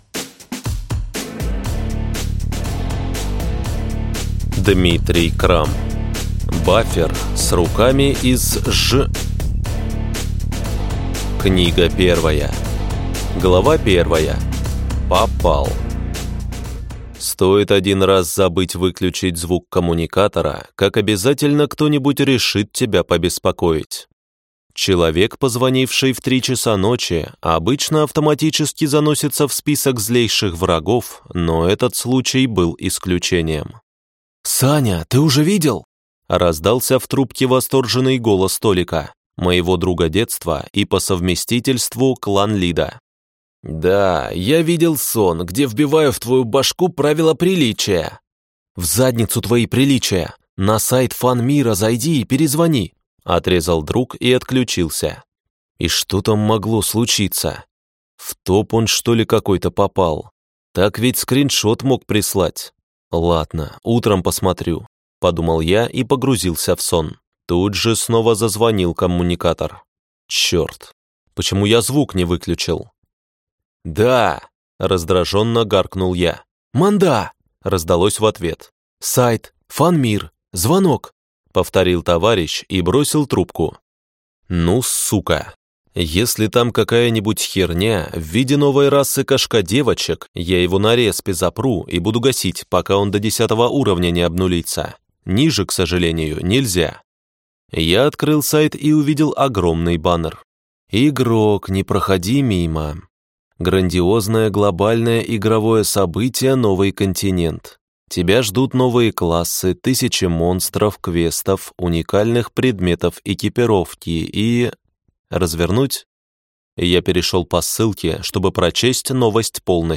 Аудиокнига Бафер с руками из ж… Книга 1 | Библиотека аудиокниг